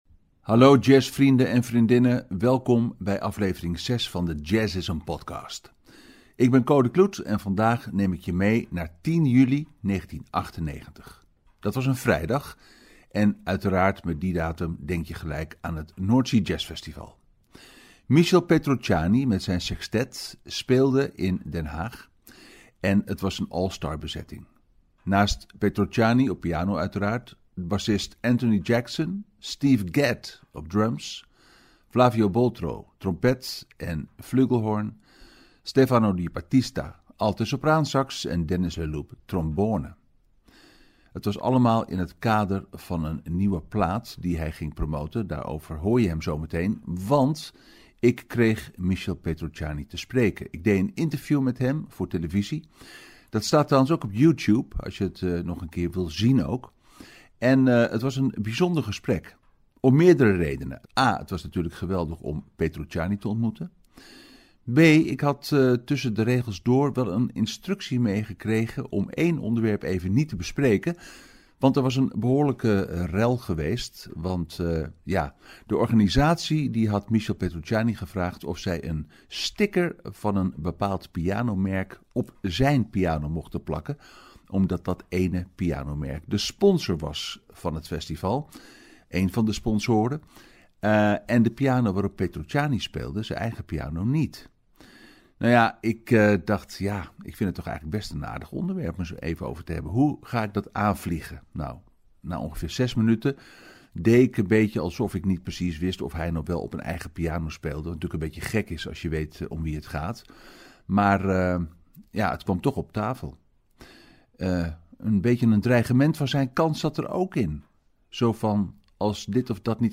Michel Petrucciani speelt dan op North Sea Jazz in Den Haag.